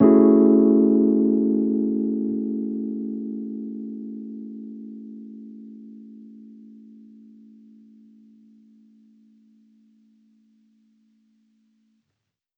Index of /musicradar/jazz-keys-samples/Chord Hits/Electric Piano 1
JK_ElPiano1_Chord-Amaj13.wav